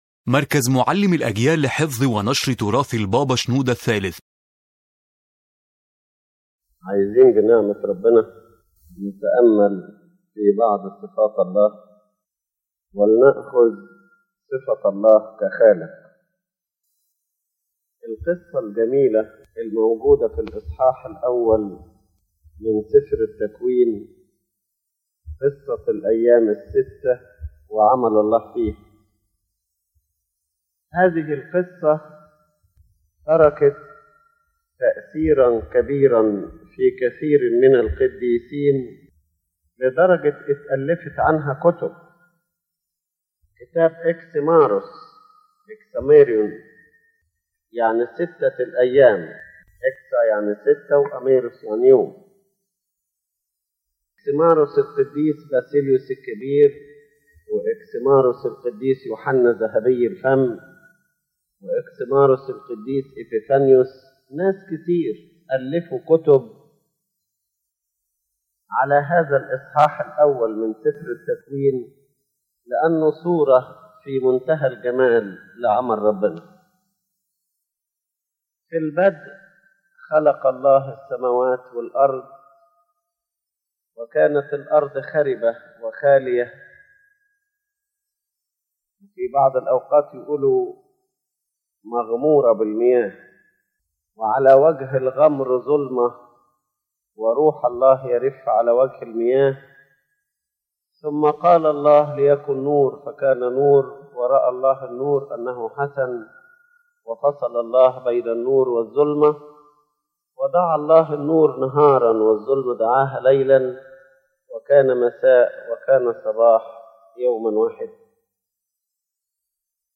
This lecture contemplates the attribute of God as Creator, where God appears in the act of creation as a wonderful God in His wisdom, organized in His management, and creative in His beauty.